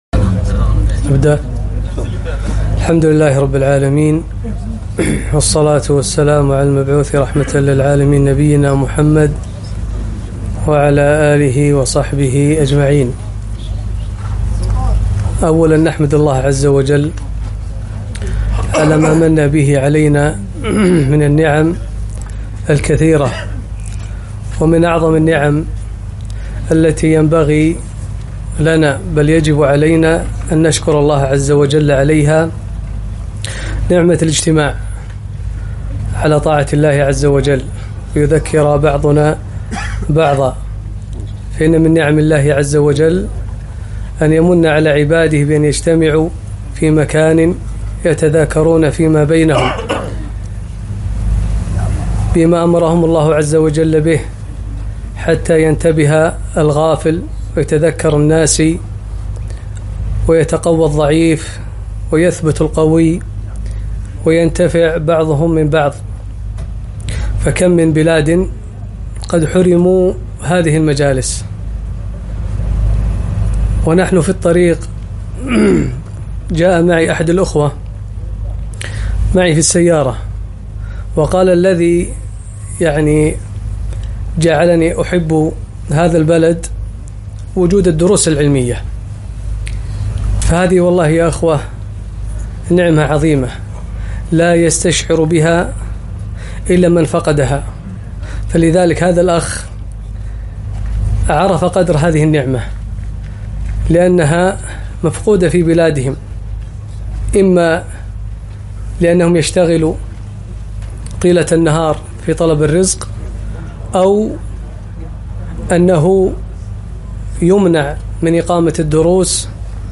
محاضرة قيّمة - ( أسباب النجاة )